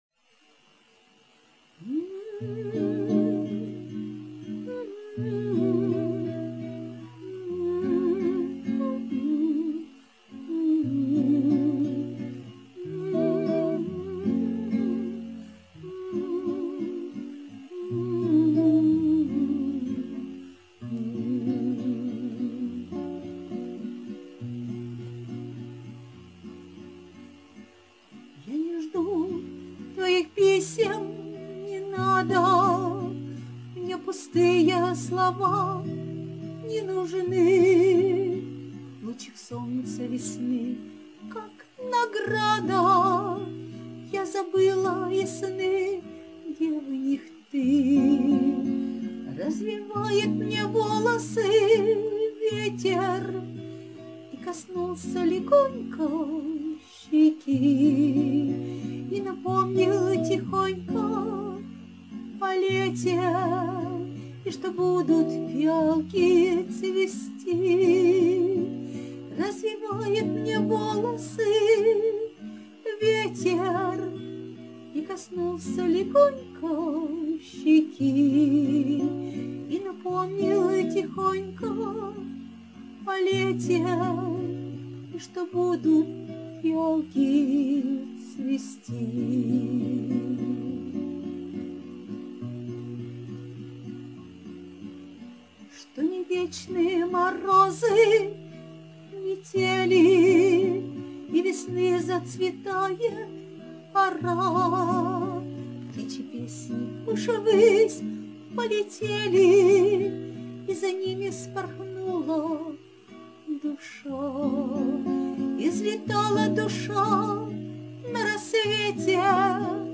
А песня получилась просто шикарной, хоть и грустной, но очень красивой! give_rose give_rose give_rose